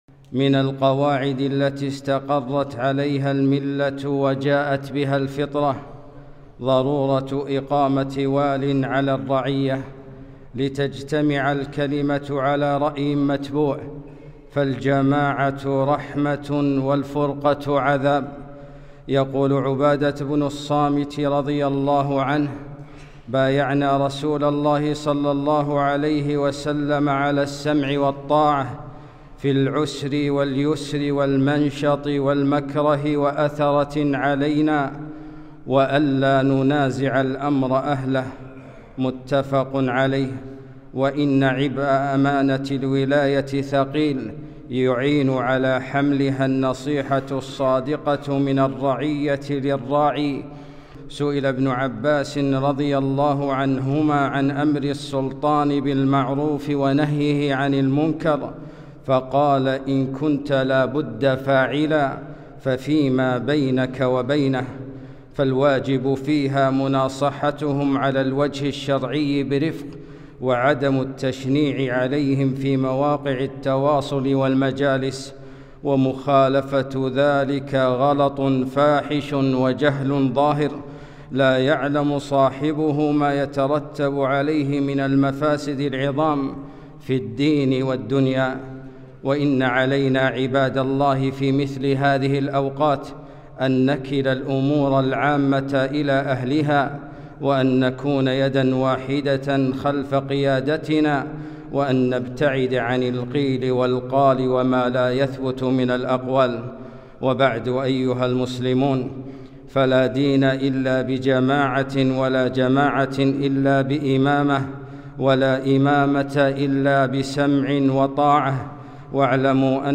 خطبة - لا جماعة إلا بسمع وطاعة